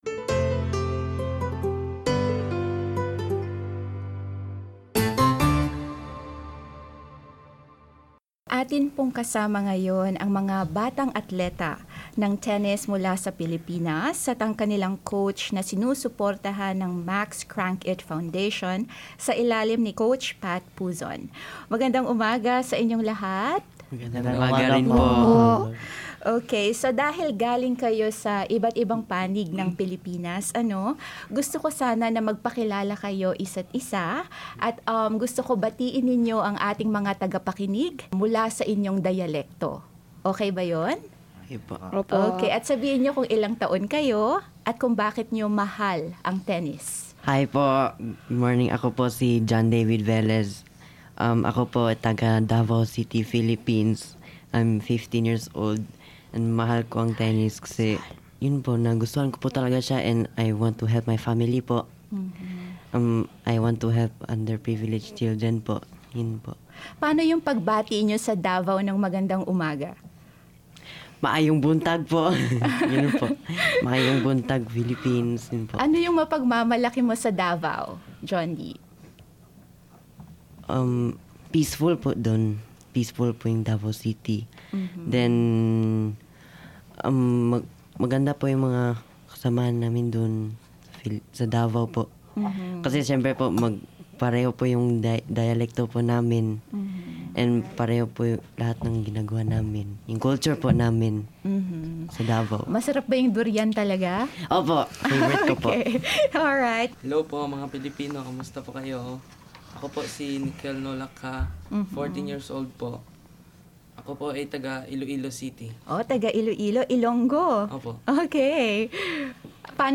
final_digital_cyb_radio_interview_tennis.mp3